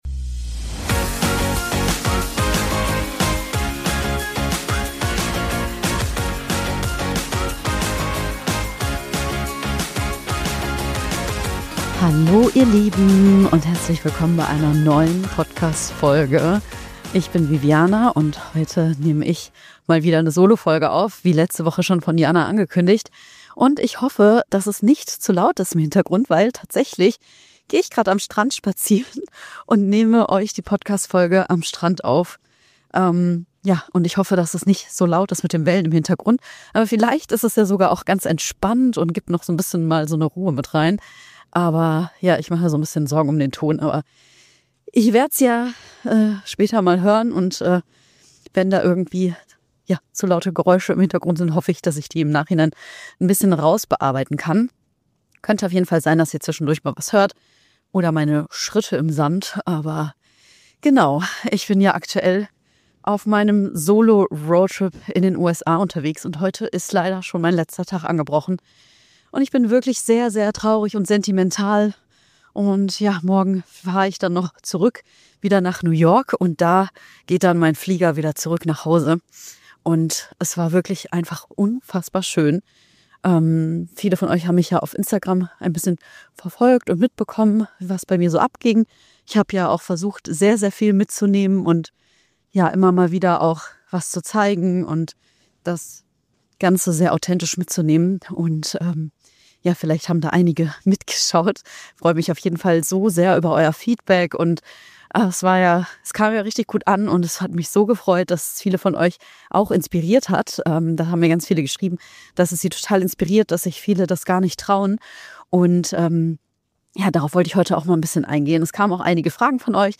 In dieser Solo Folge nehme ich euch mit auf meinen Roadtrip und erzähle, wie es überhaupt dazu kam, dass ich alleine losgezogen bin.